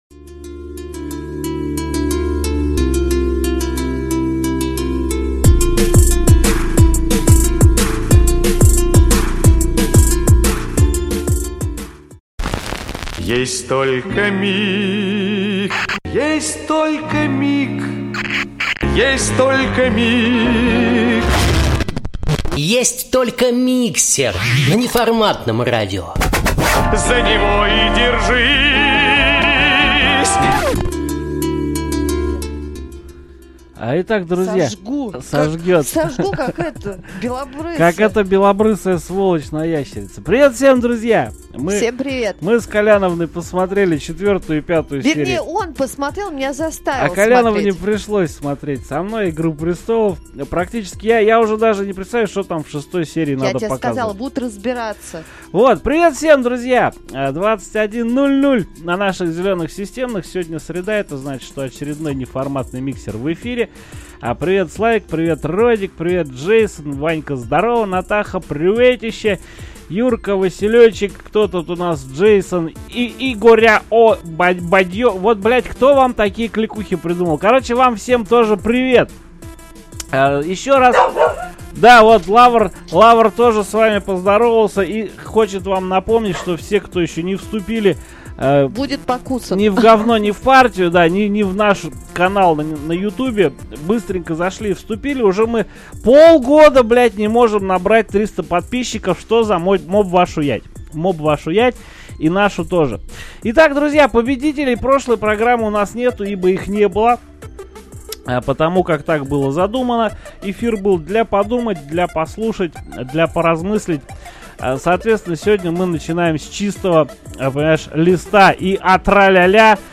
Программа получилась громкая и насыщенная.